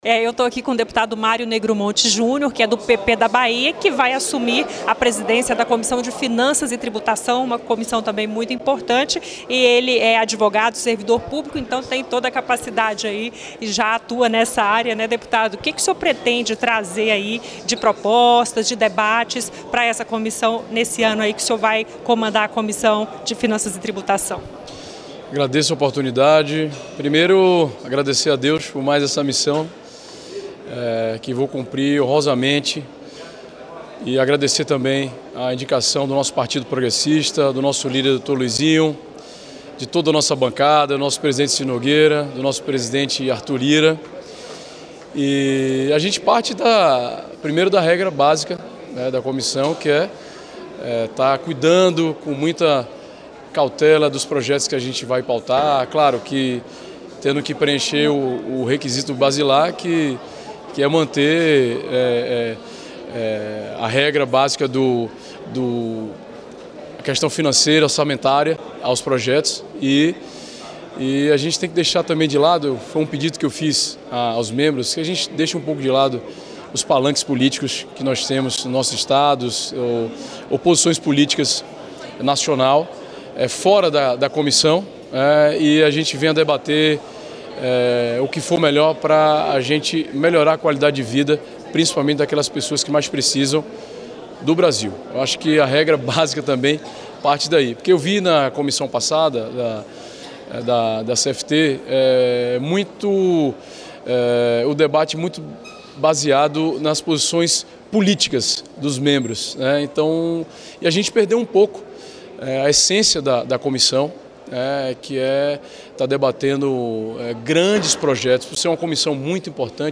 O parlamentar conversou com a equipe da Rádio e TV Câmara após ter sido escolhido para comandar a comissão nesta quarta-feira (6). Segundo Negromonte Jr., é preciso deixar de lado disputas políticas dentro da comissão, para que os trabalhos no colegiado possam ser conduzidos com foco em grandes projetos para o país.